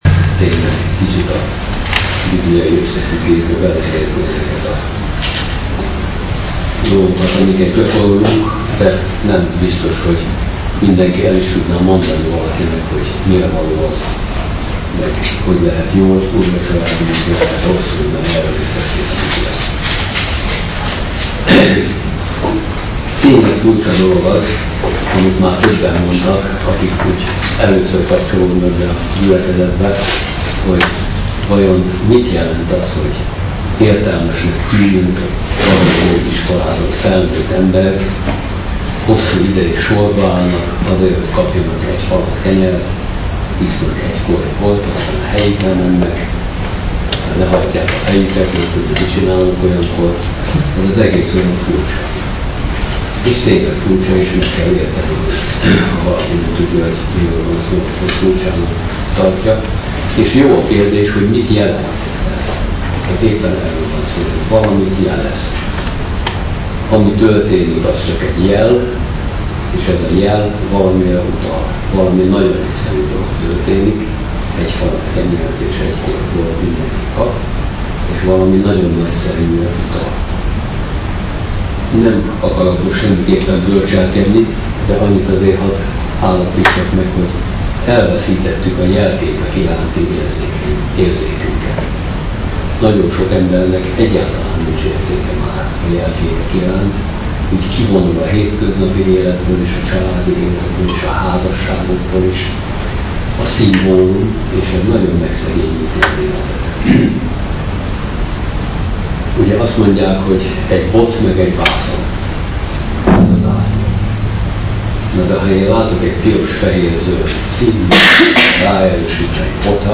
A felvétel elég rossz min?ségű.